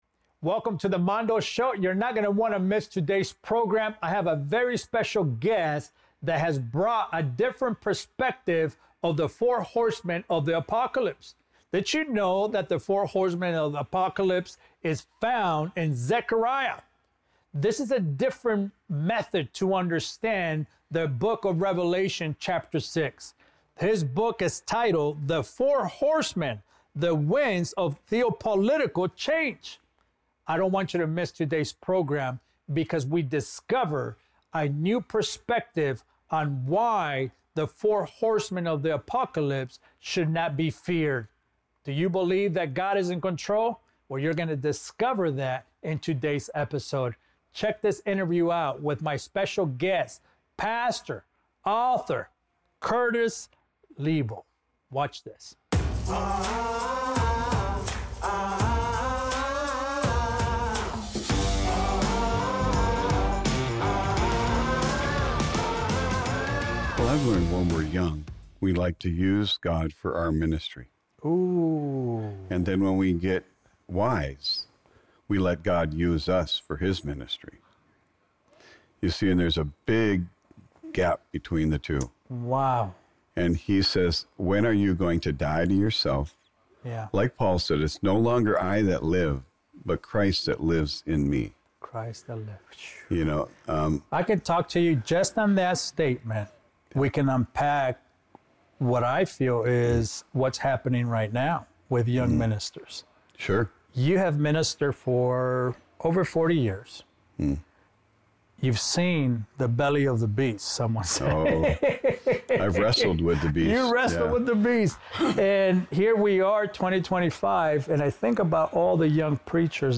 Don’t miss this eye-opening discussion!